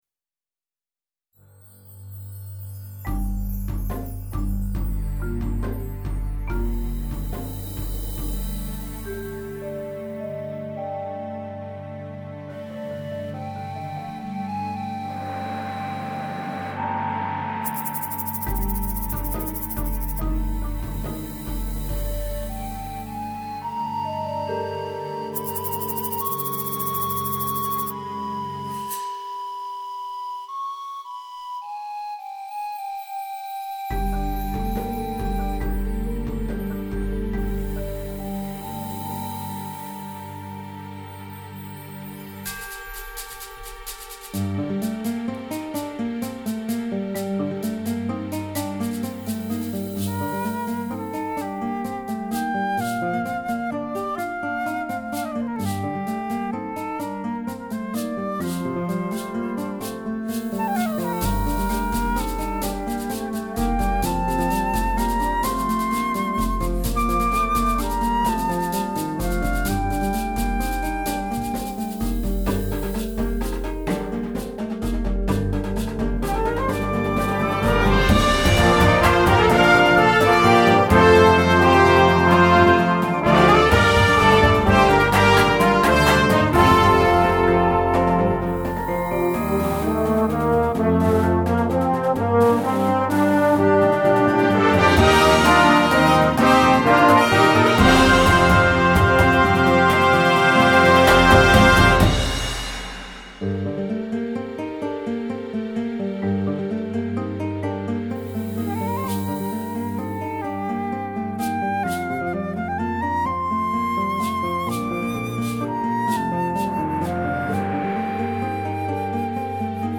Mvt. 2 (winds and percussion)